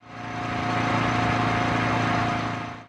CosmicRageSounds / ogg / general / highway / oldcar / tovertake6.ogg